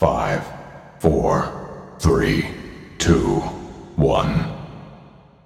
Play, download and share Countdown original sound button!!!!
countdown.mp3